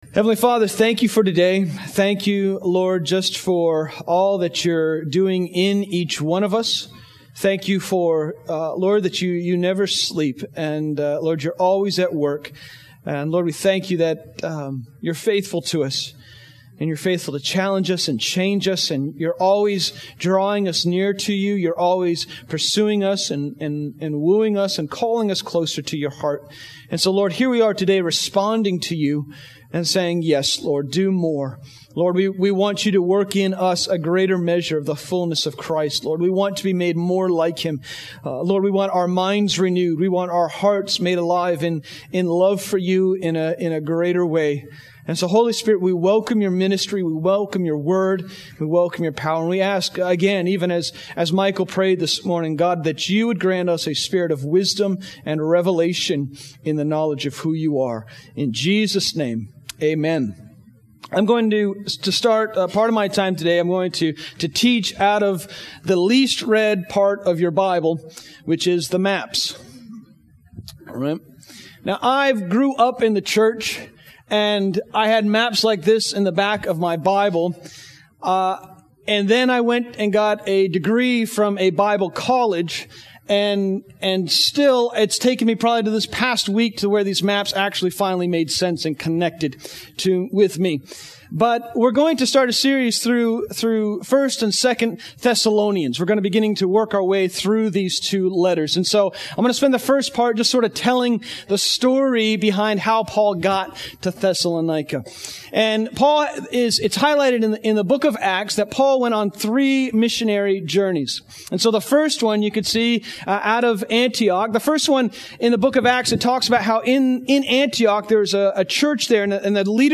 04/29/18